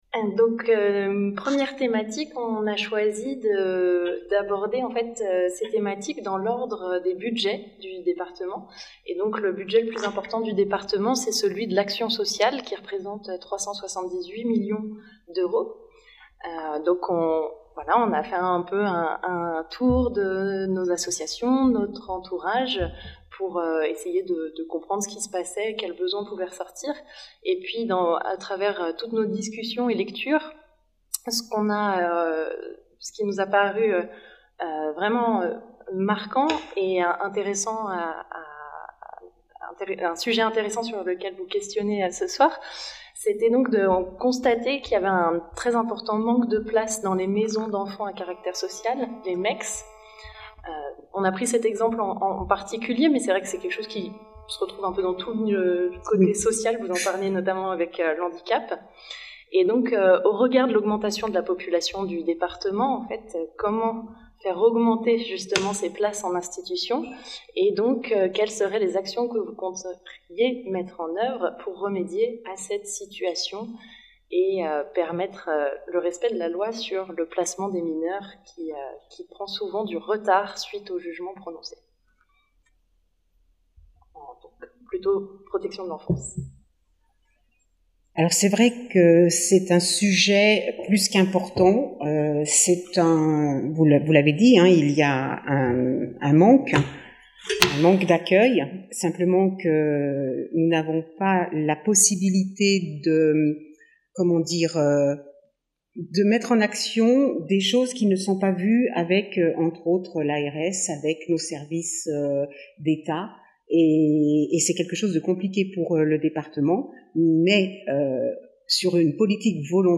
Deux binômes ont répondu à notre demande d’interview, nous les en remercions et nous réjouissons de vous partager les enregistrements de ces rencontres d’une heure, enregistrées sans montage, avec un simple découpage par thématiques de questions.